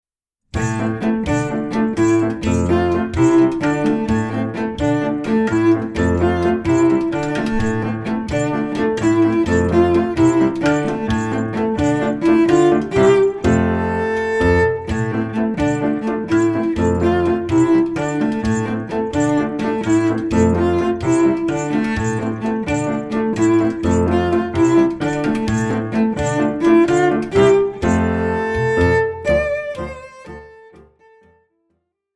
• Besetzung Violoncello und Klavier